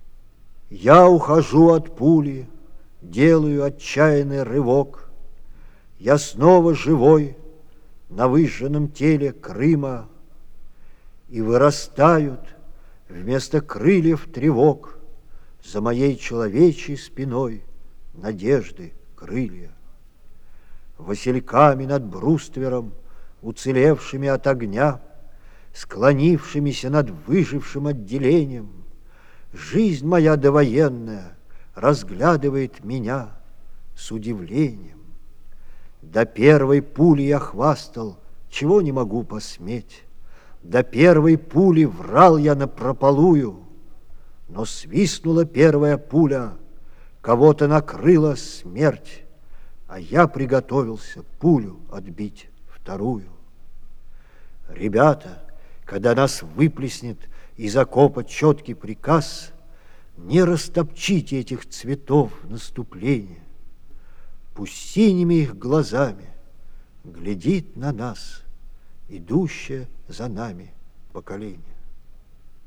Многие песни уже звучали на Завалинке,но здесь подборка песен и стихов на одном диске в исполнении автора.